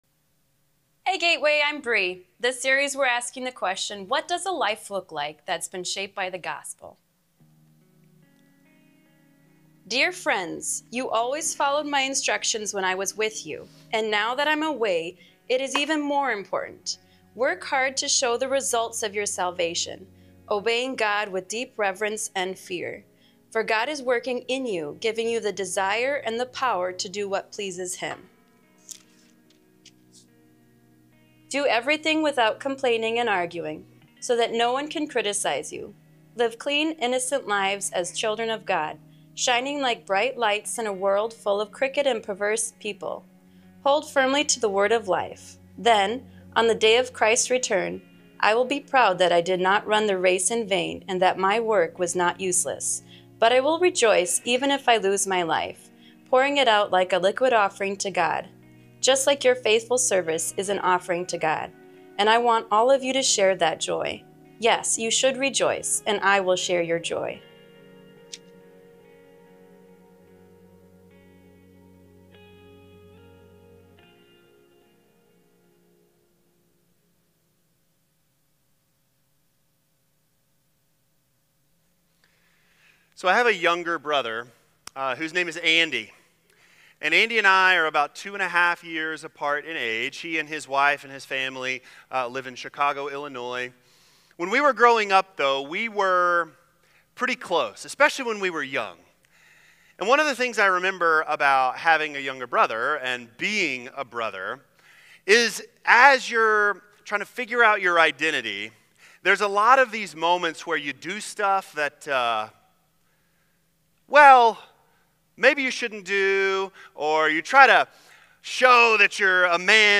Joyful-Obedience-Sermon-5.18.25.m4a